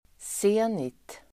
Ladda ner uttalet
Uttal: [s'e:nit]